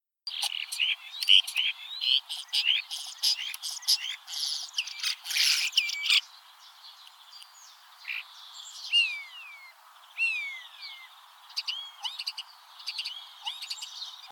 chant étourneau B